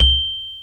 Xylophone C Major